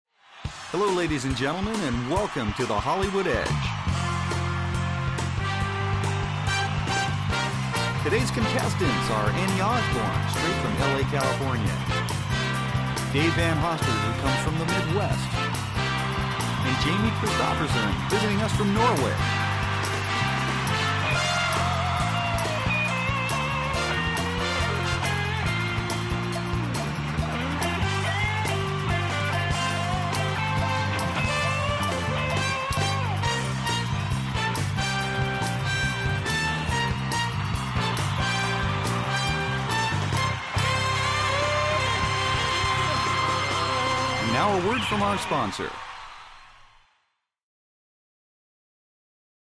Television; Game Show Opening With Music, Announcer And Crowd. From Close.